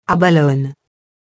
• SECTION 1: FRENCH PRONUNCIATION OF ABALONE
Audio tag with controls allows the user to listen to the French Pronunciation of the word abalone.